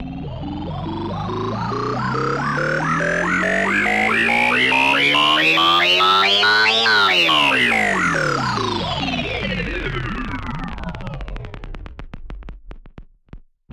Звуки мутаций
Звук: герой увеличивается